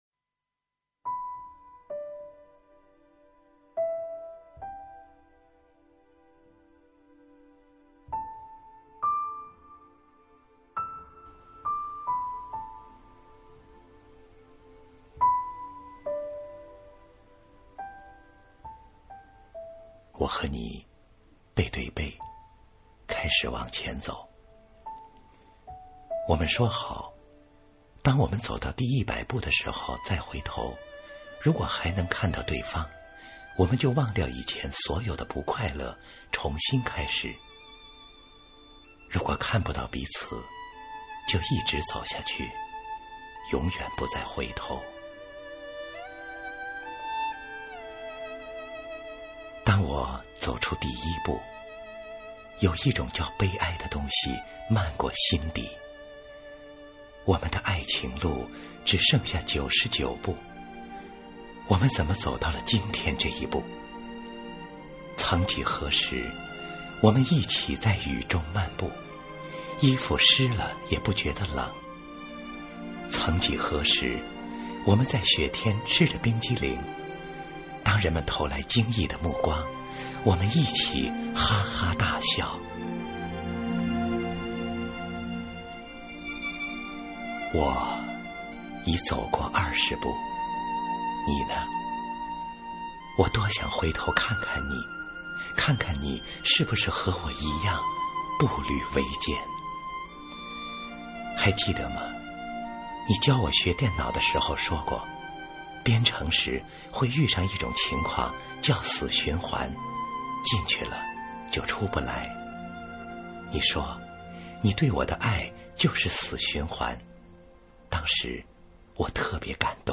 经典朗诵欣赏